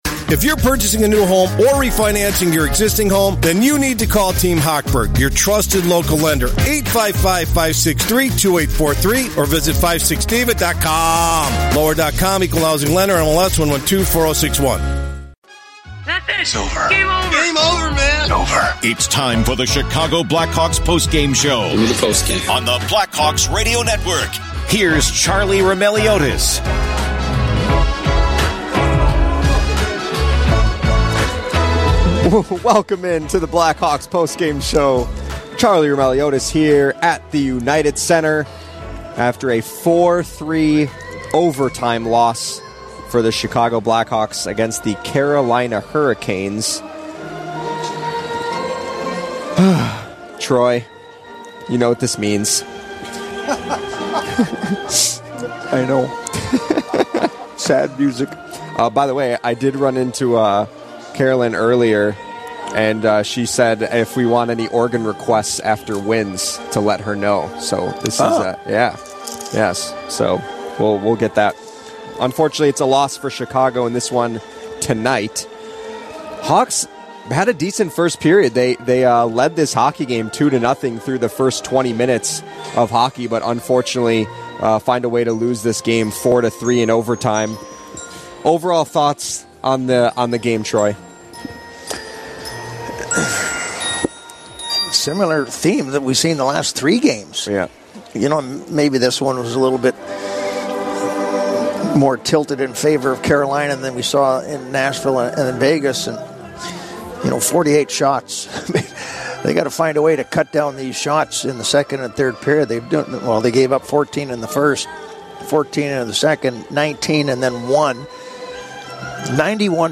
Later in the show, hear sound from Taylor Hall, Philipp Kurashev and Anders Sörensen.